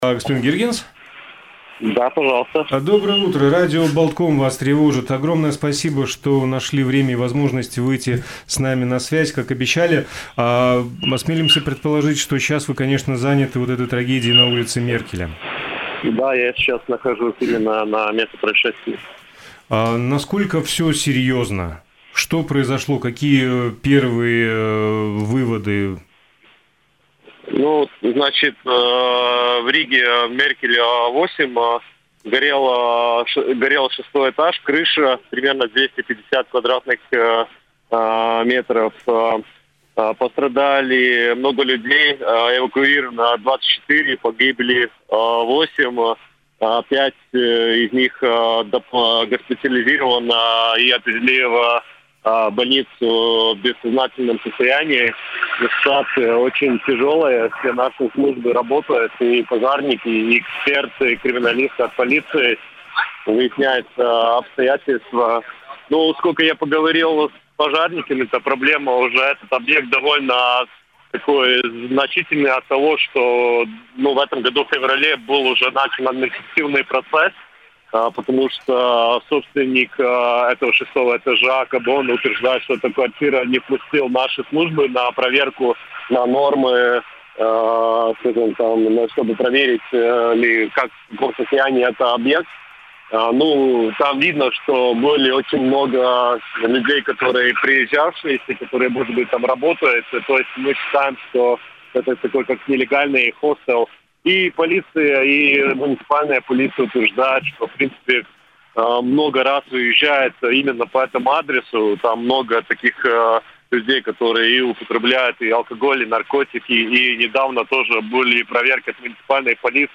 Министр внутренних дел Сандис Гиргенс, находясь на месте пожара, эксклюзивно для радио Baltkom рассказал о самых актуальных деталях ЧП.